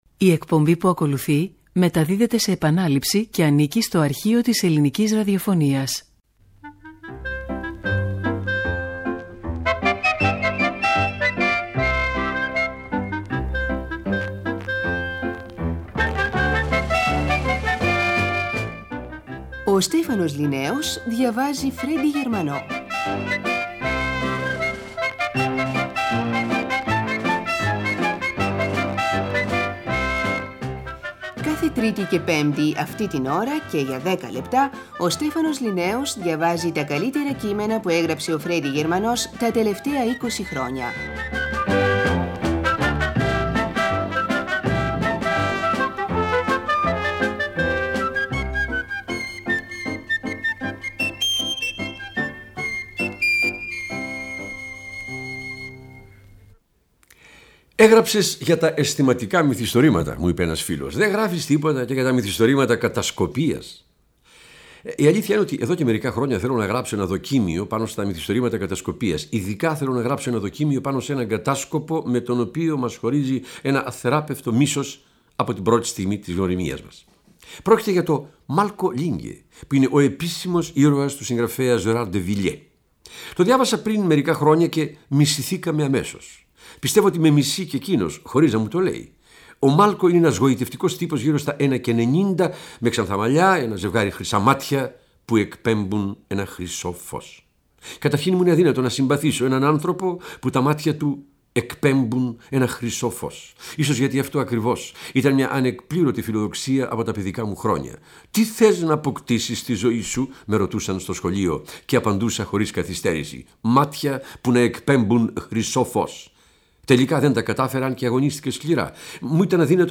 Ο Στέφανος Ληναίος διαβάζει Φρέντυ Γερμανό – «Ο κατάσκοπος που μισώ» | Αρχείο Ελληνικής Ραδιοφωνίας (1985)
Ένα 10λεπτο στο οποίο ο Στέφανος Ληναίος διαβάζει τα καλύτερα κείμενα που έγραψε ο Φρέντυ Γερμανός την εικοσαετία (1965-1985).